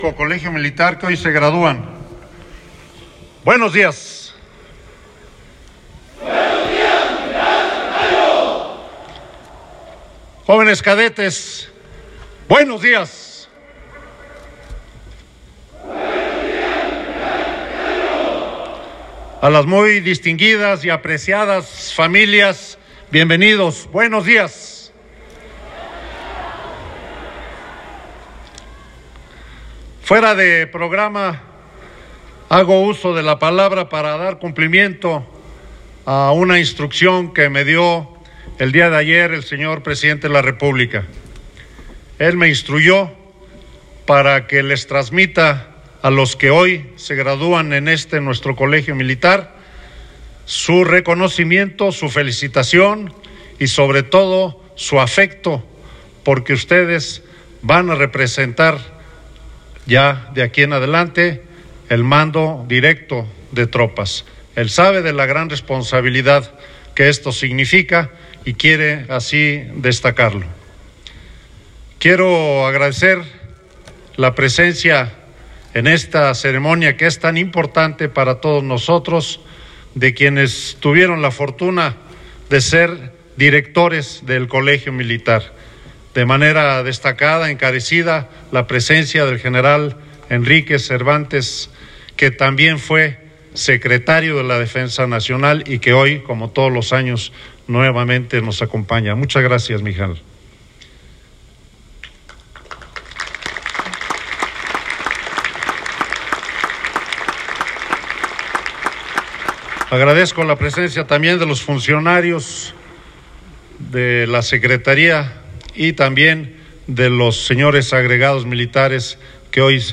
“México requiere de fuerzas armadas cada vez más fuertes” General Salvador Cienfuegos Zepeda Secretario S.D.N Ceremonia de Graduación de alumnos del Heroico Colegio Militar
RADIO